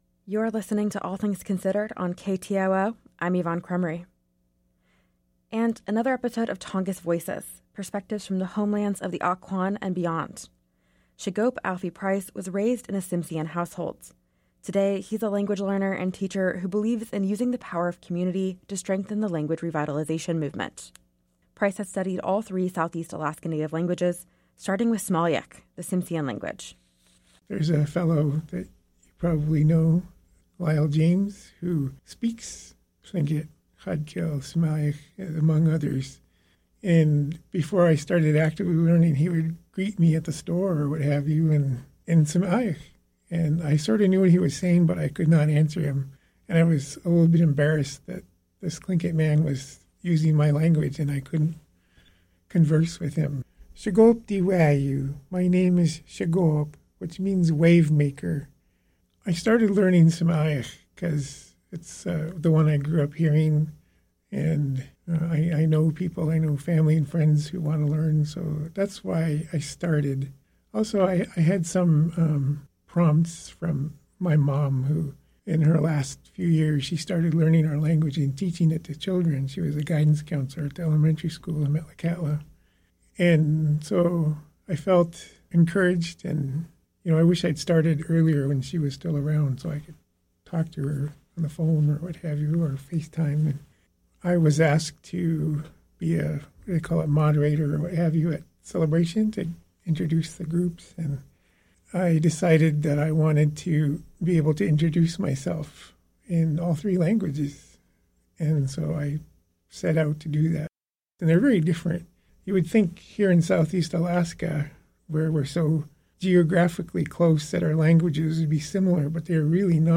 Newscast – Monday, April 1, 2024